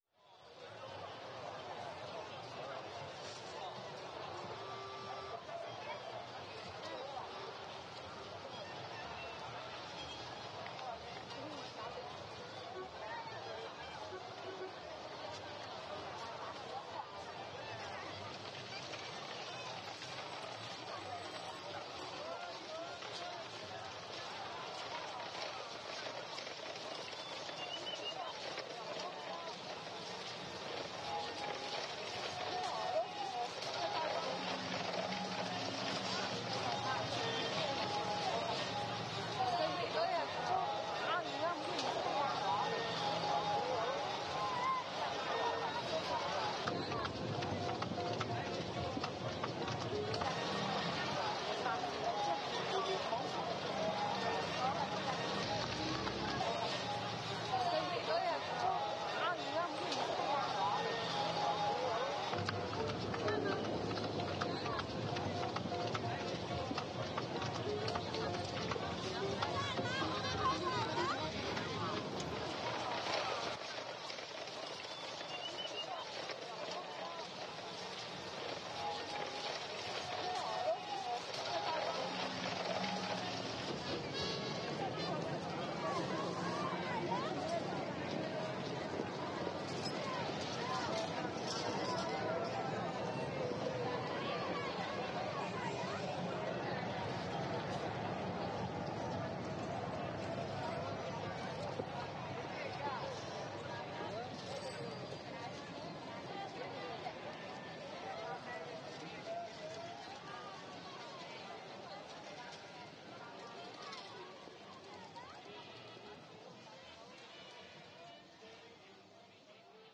街道背景音.wav